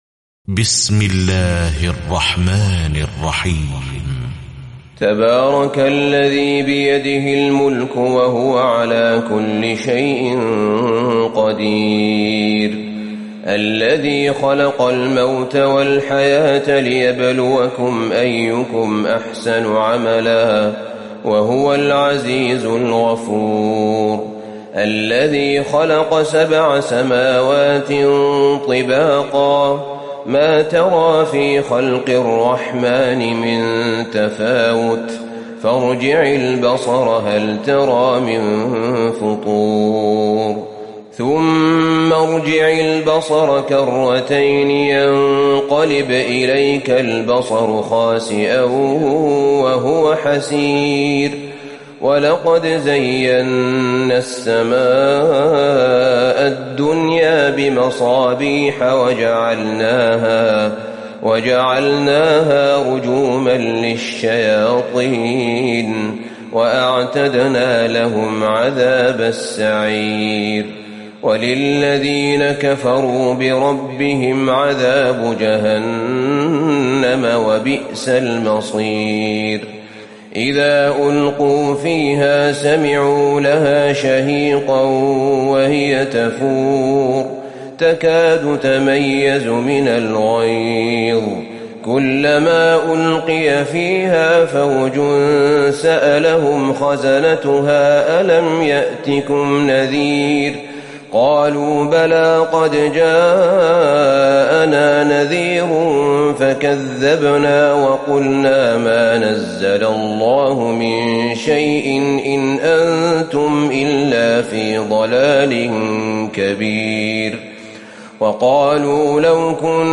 تراويح ليلة 28 رمضان 1438هـ من سورة الملك الى نوح Taraweeh 28 st night Ramadan 1438H from Surah Al-Mulk to Nooh > تراويح الحرم النبوي عام 1438 🕌 > التراويح - تلاوات الحرمين